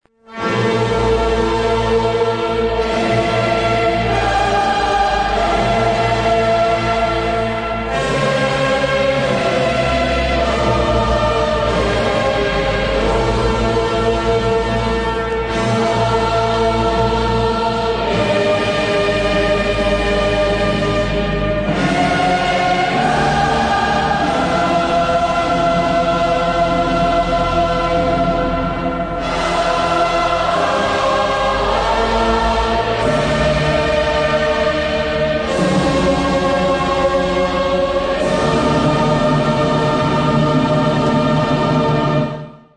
bombast-orchestral dark-ambient / industrial album